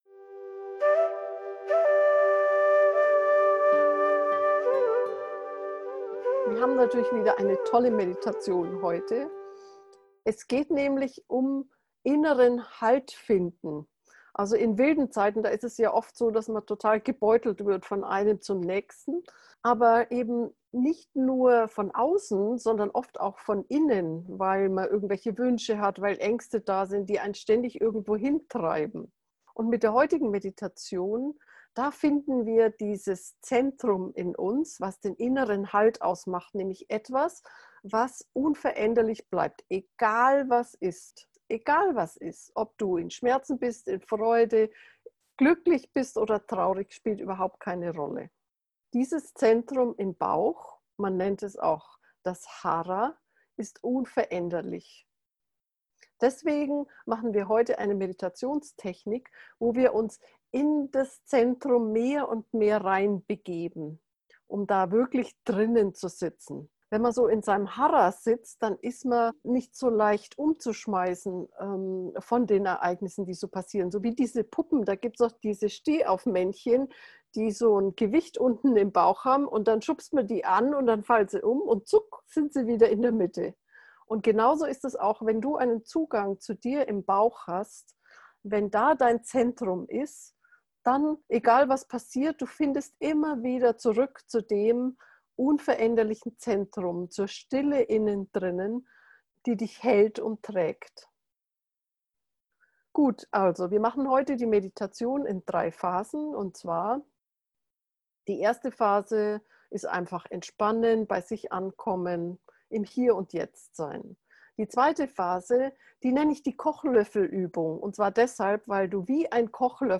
Geführte Meditationen Folge 39: Der innere Halt im Bauch | Geführte Hara Meditation Play Episode Pause Episode Mute/Unmute Episode Rewind 10 Seconds 1x Fast Forward 30 seconds 00:00 / 17:06 Abonnieren Teilen RSS Feed Teilen Link Embed